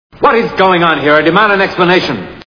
Dr Strangelove Movie Sound Bites